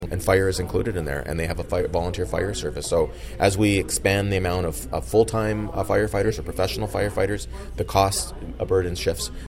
Mayor Mitch Panciuk tells Quinte News the report has serious implications for Ward 2 residents.